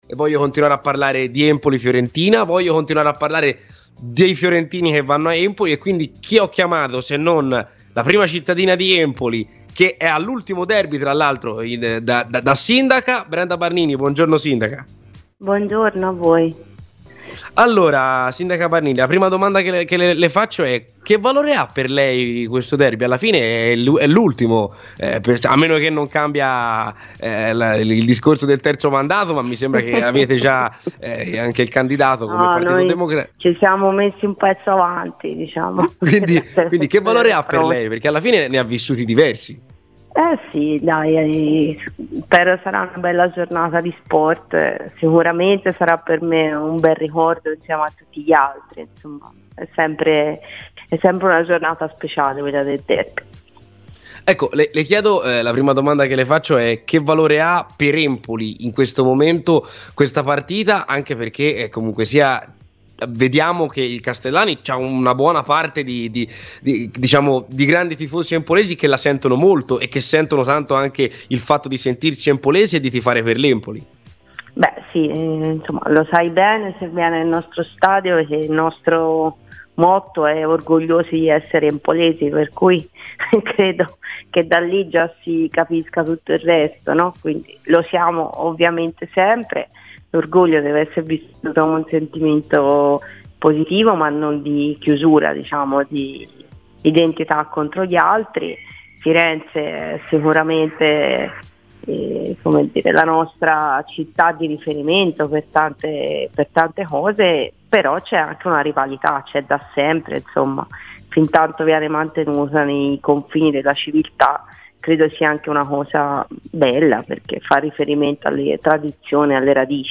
Brenda Barnini a Radio Firenze Viola